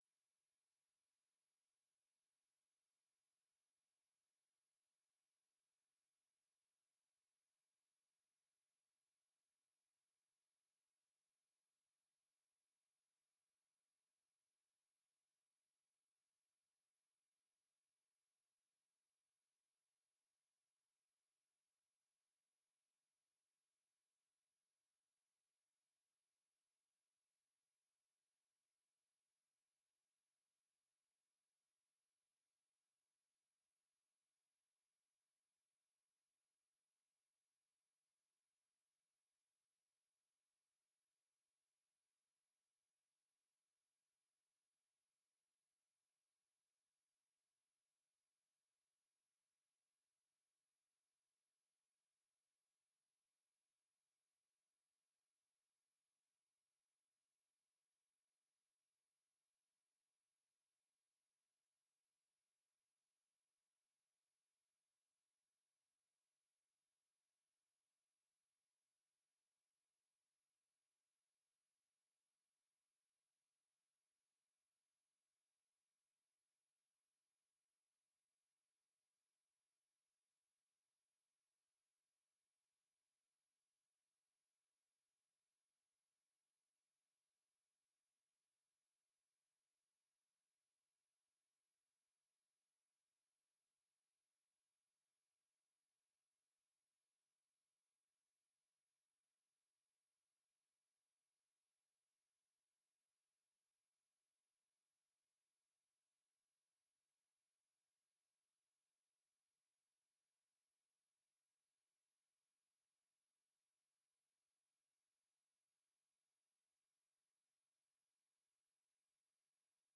beeldvormende raad 10 april 2025 19:30:00, Gemeente Doetinchem
Locatie: Raadzaal